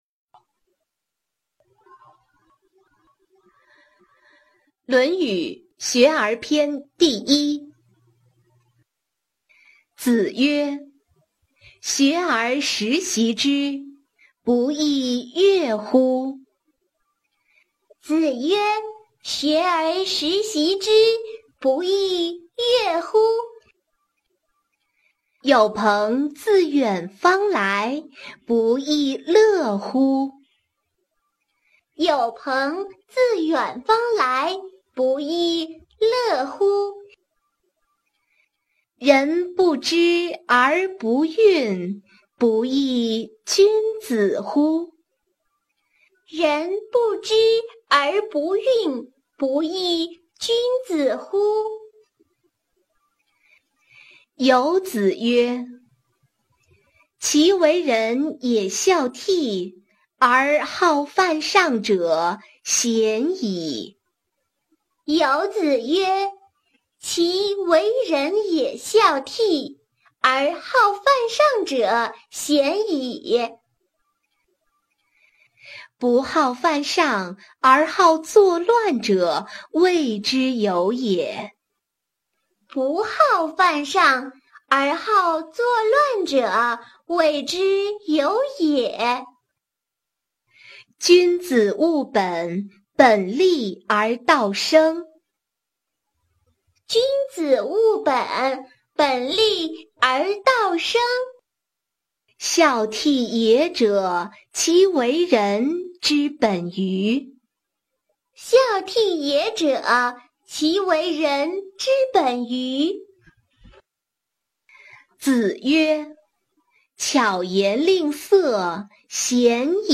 四书《大学、论语、孟子、中庸》女声版童音跟读